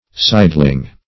Sideling - definition of Sideling - synonyms, pronunciation, spelling from Free Dictionary
Sideling \Side"ling\, adv.